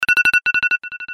без слов
короткие
звонкие